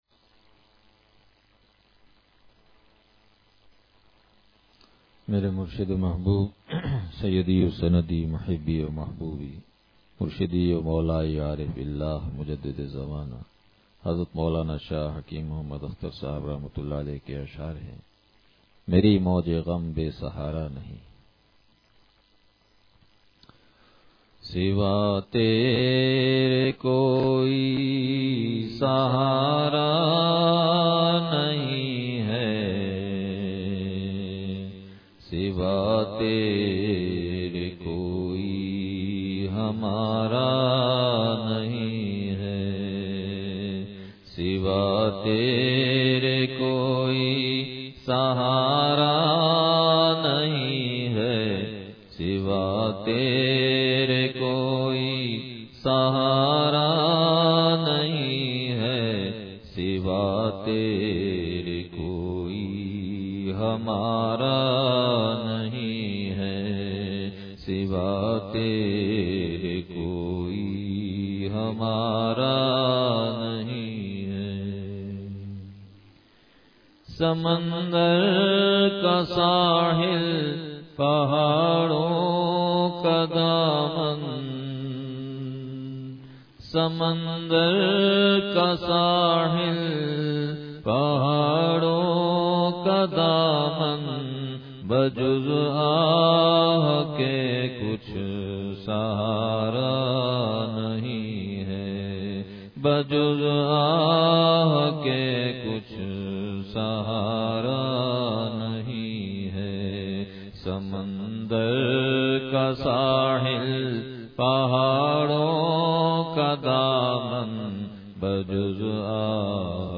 مری موج غم بے سہارا نہیں ہے – چار اعمال کا بیان